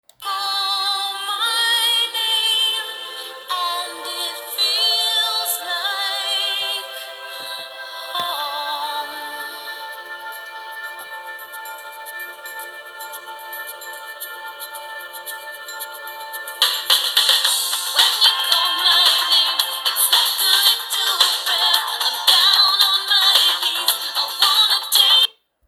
It looks like the sound too loud.
I made both recording 20 cm away from the speaker with my mobile phone. Sorry about high sound levels!